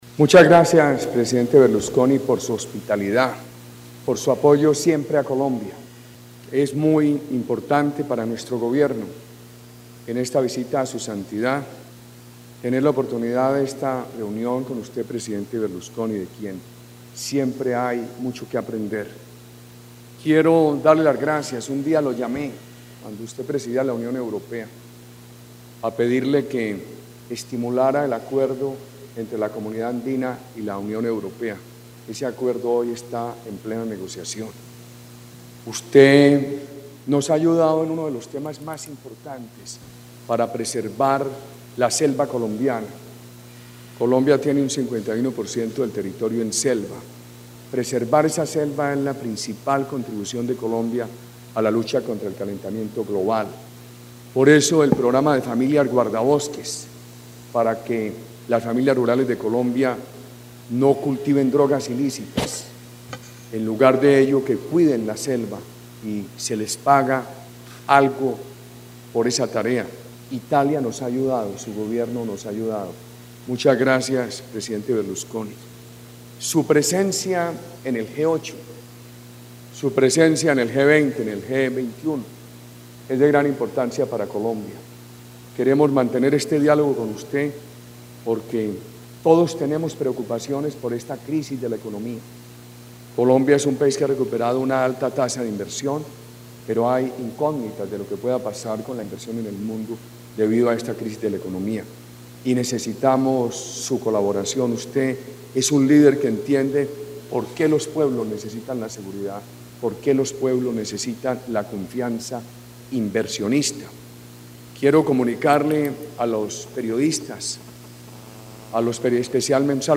Escuchar Palabras del Presidente Uribe, luego de su encuentro con el Primer Ministro de Italia, Silvio Berlusconi